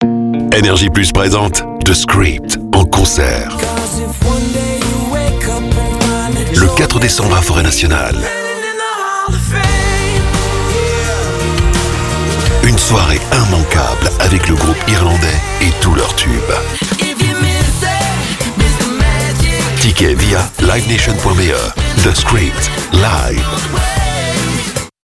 Voix off
Bande annonce concert NRJ
Travaillant depuis mon propre studio professionnel entièrement équipé, je dispose d'une cabine acoustique et d'un micro Neumann U87 pour garantir une qualité sonore optimale.
38 - 82 ans - Basse